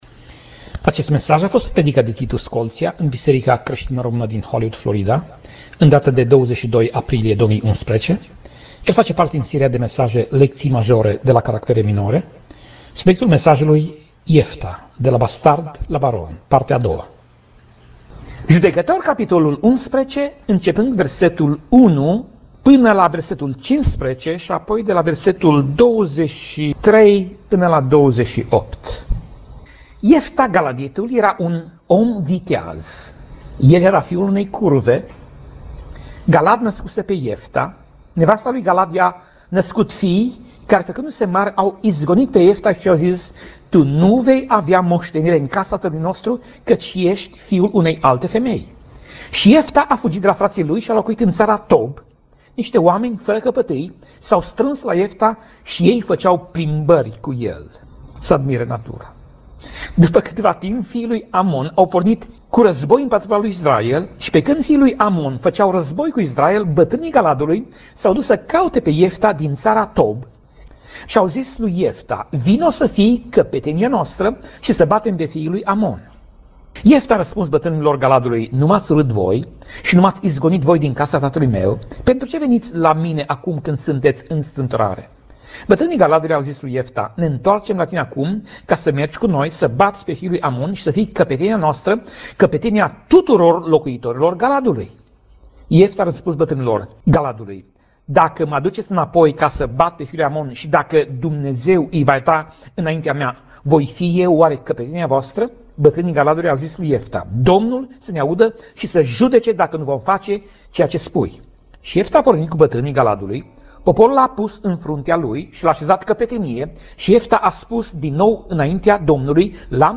Pasaj Biblie: Judecatorii 11:1 - Judecatorii 11:28 Tip Mesaj: Predica